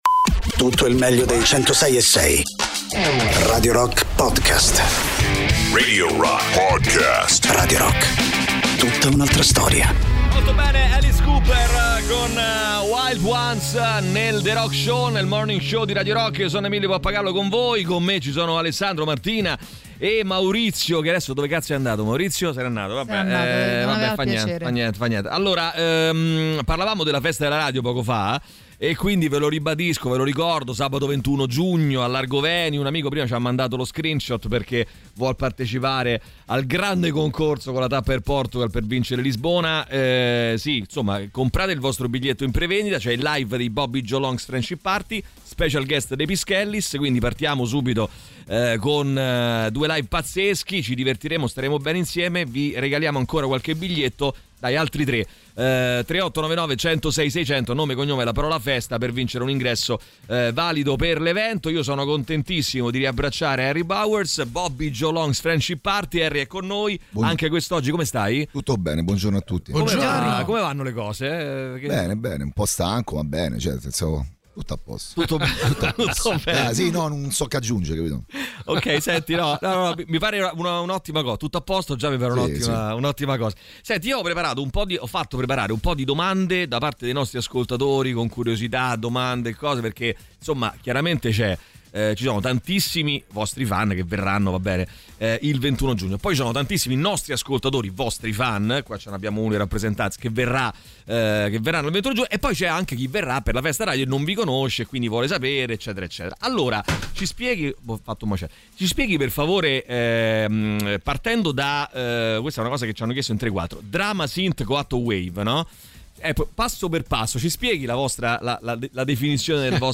Interviste: Bobby Joe Long's Friendship Party (11-06-25)